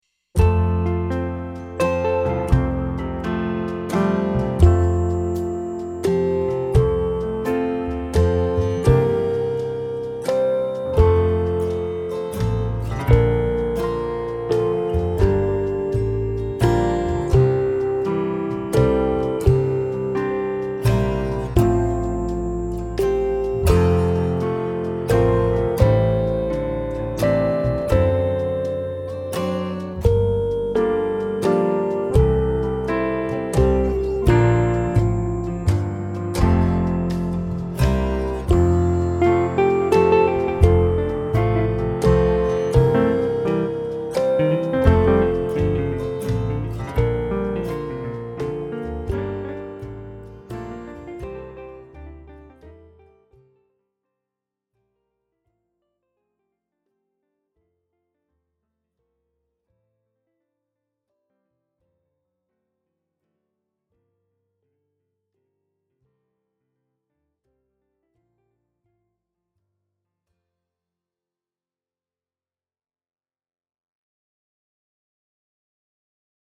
Spied šeit, lai paklausītos Demo ar melodiju